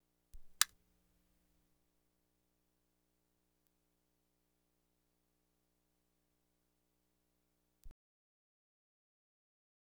Light Switch Snap Type Sound Effect
Download a high-quality light switch snap type sound effect.
light-switch-snap-type.wav